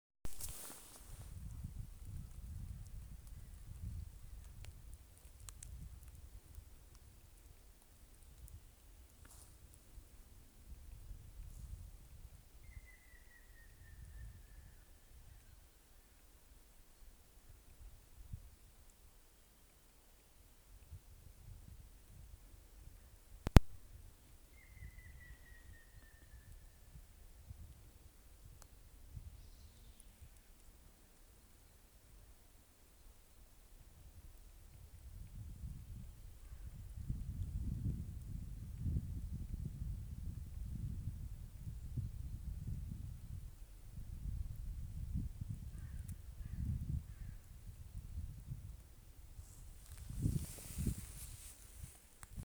седой дятел, Picus canus
Administratīvā teritorijaRīga
СтатусПоёт
Примечания/Bungo.